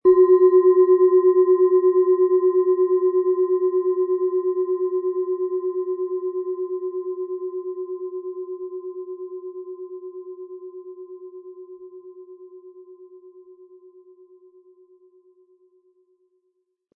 Planetenton 1
Planetenschale® In den Bauch spüren & Gefühle erkennen und leben mit Mond, Ø 12,6 cm inkl. Klöppel
Im Audio-Player - Jetzt reinhören hören Sie genau den Original-Klang der angebotenen Schale. Wir haben versucht den Ton so authentisch wie machbar aufzunehmen, damit Sie gut wahrnehmen können, wie die Klangschale klingen wird.
Mit einem sanften Anspiel "zaubern" Sie aus der Mond mit dem beigelegten Klöppel harmonische Töne.
MaterialBronze